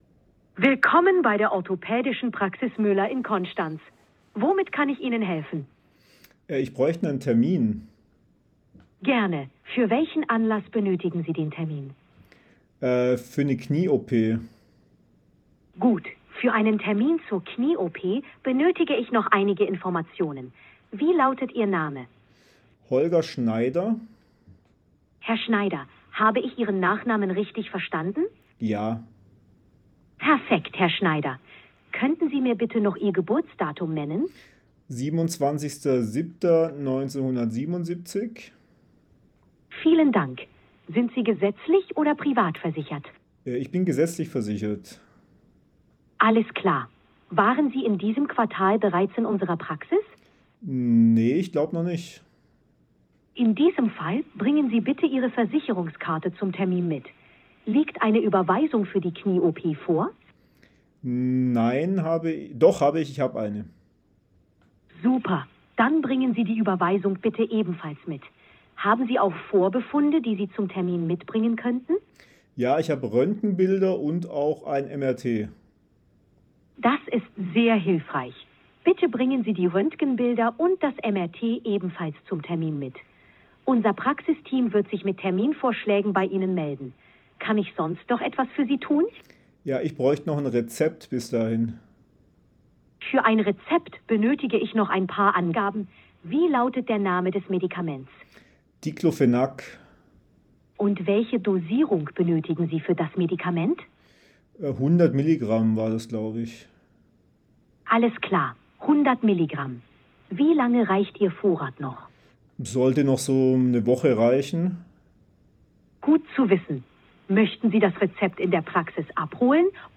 Demo-AI-Assistent_audio1.wav